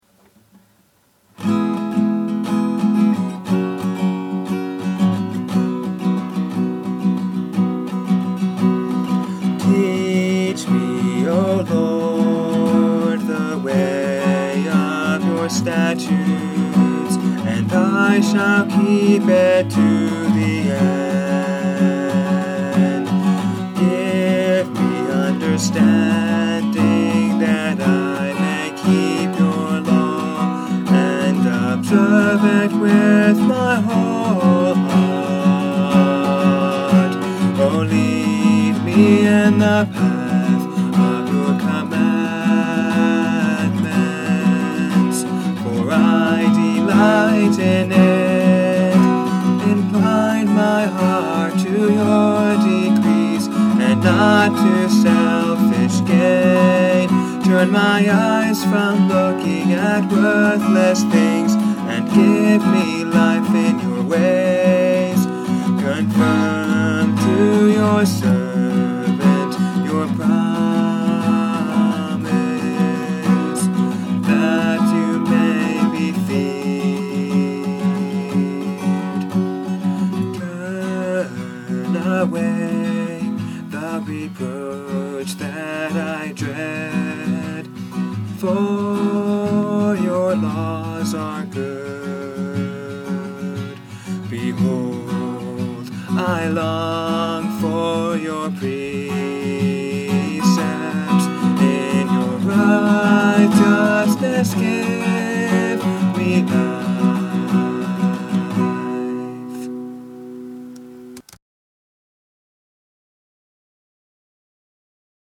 I hope to make some cleaner sounding recordings later, but I thought I would post the rough recordings I have now.